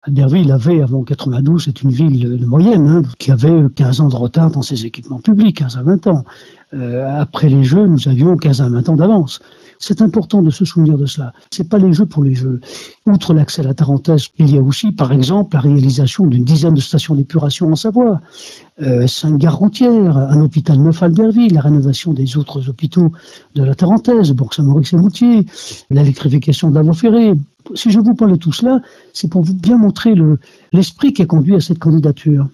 Albert Gibello, ancien maire d’Albertville était adjoint aux sports lors des JO en 92.